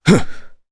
Crow-Vox_Landing_jp.wav